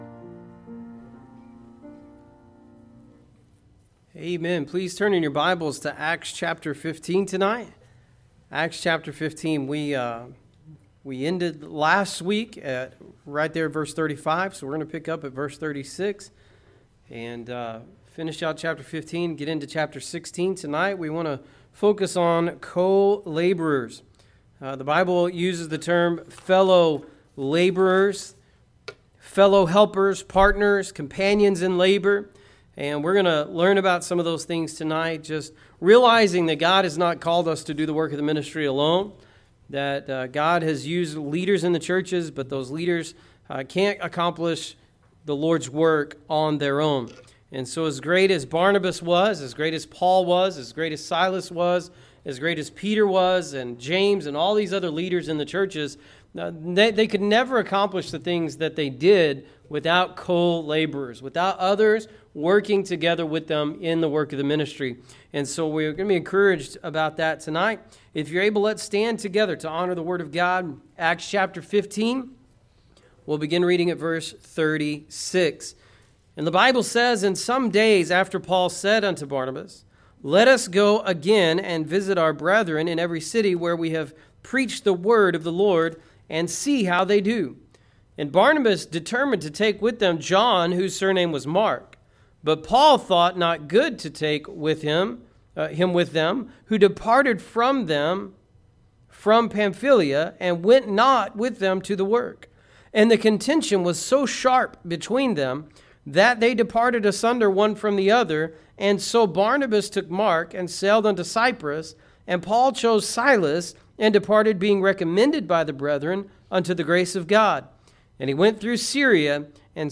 It is my desire to subscribe your audio sermon and sermon outline.